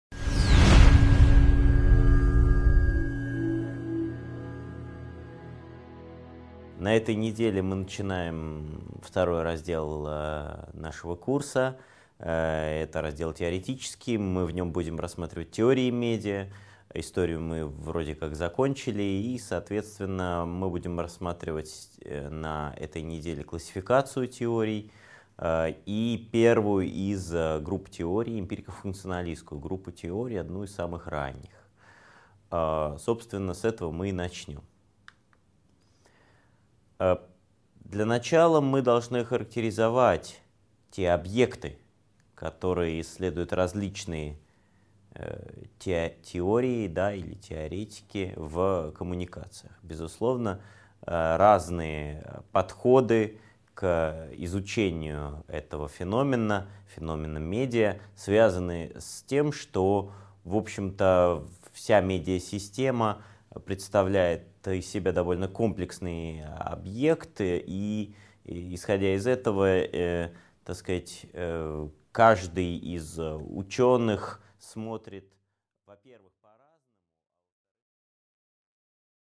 Аудиокнига 5.1 Объекты исследования в науке о коммуникациях | Библиотека аудиокниг